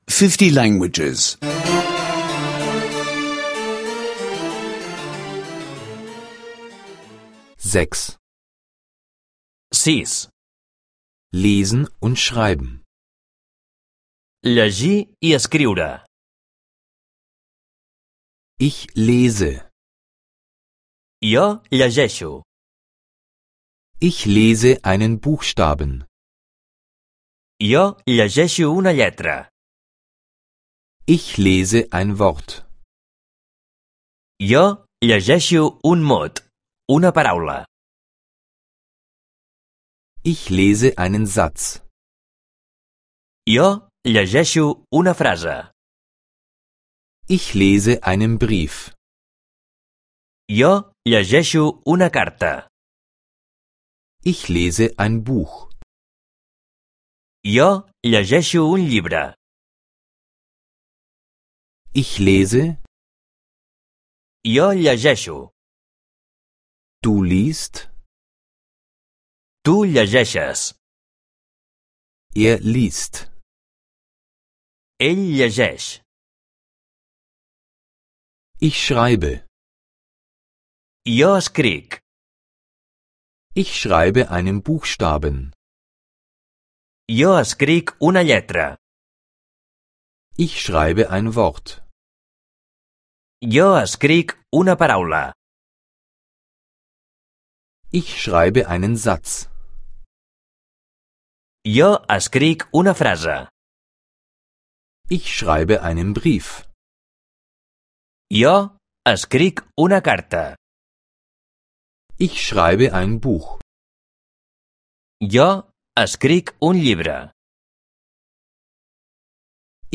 Katalanisch Audio-Lektionen, die Sie kostenlos online anhören können.